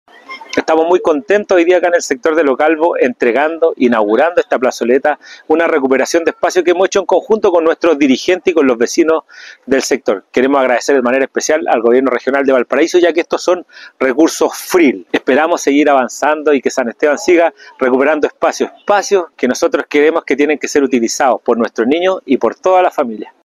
alcalde-ortega-inauguracion-plazoleta.mp3